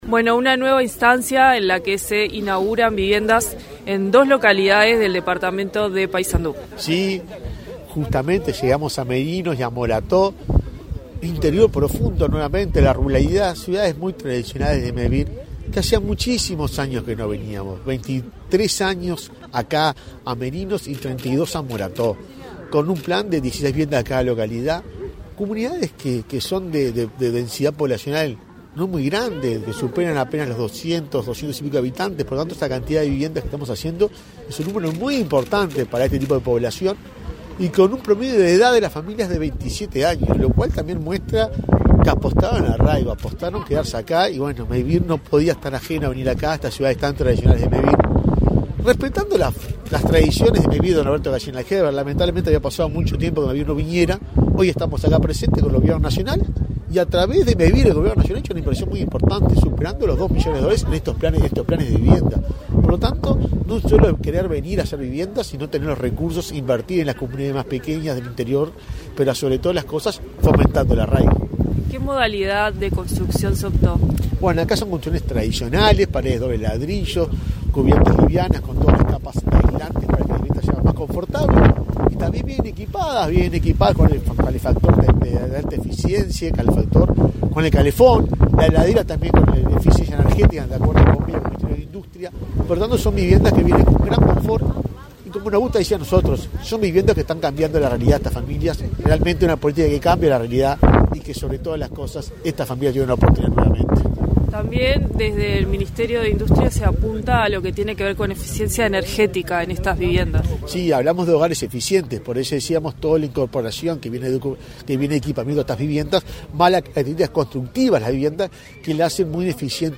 Entrevista al presidente de Mevir, Juan Pablo Delgado
Tras los eventos el presidente de Mevir, Juan Pablo Delgado, realizó declaraciones a Comunicación Presidencial.